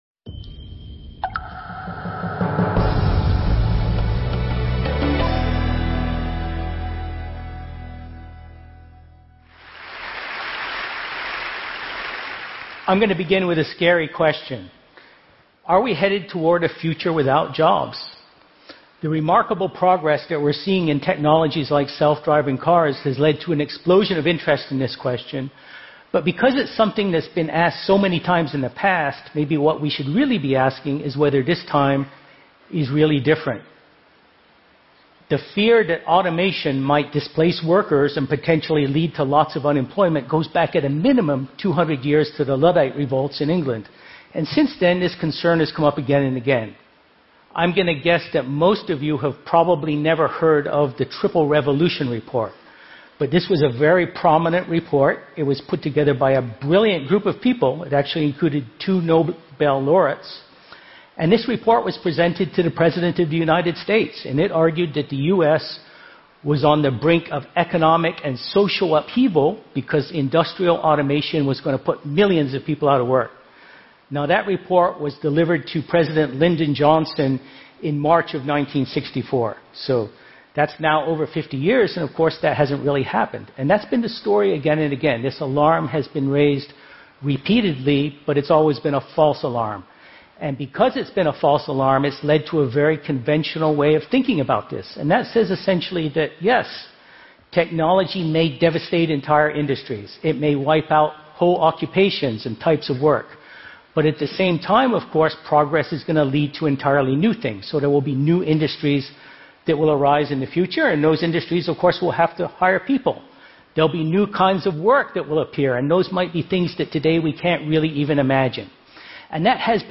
Here is the full transcript of Martin Ford’s TED Talk: How We’ll Earn Money in a Future Without Jobs.